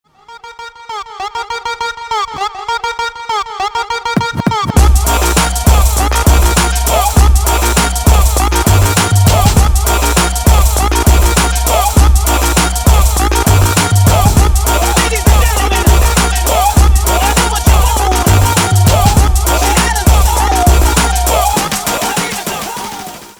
клубные